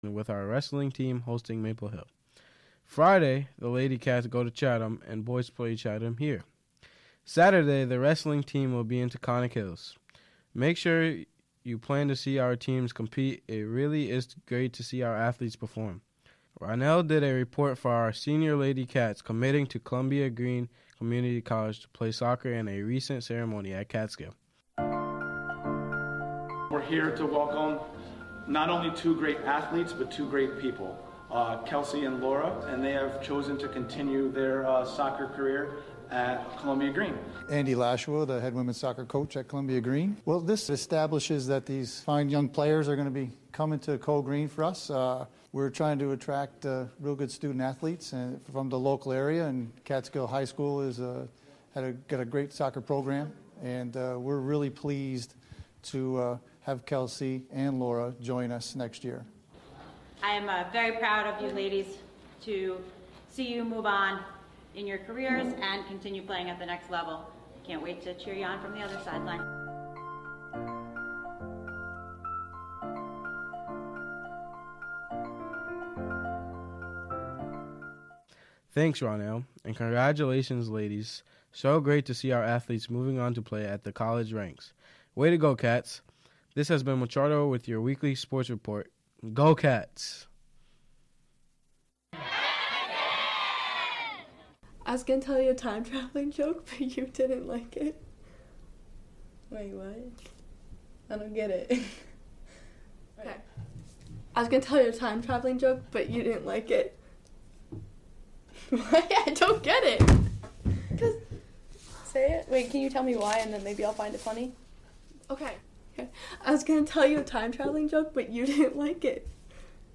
Show includes local WGXC news at beginning, and midway through.